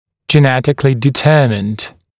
[ʤɪ’netɪkəlɪ dɪ’tɜːmɪnd][джи’нэтикэли ди’тё:минд]генетически детерминированный